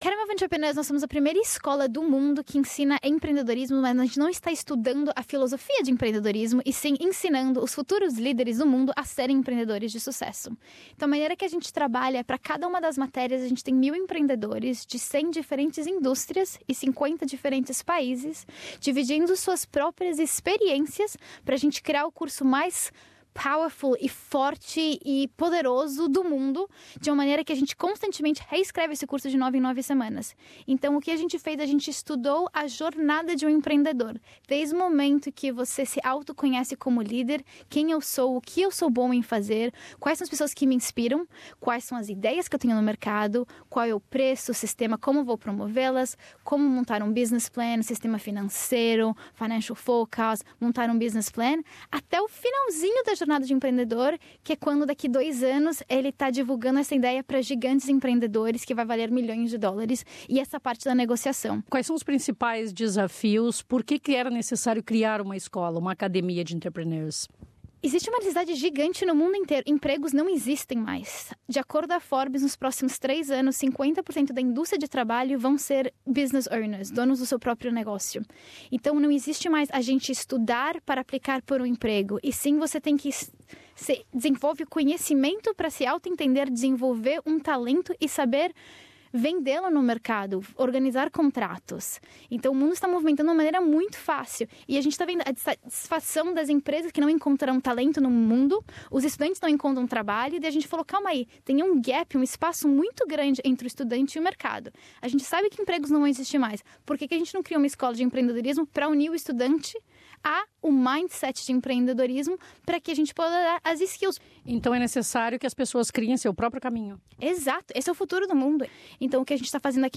Nesta entrevista à SBS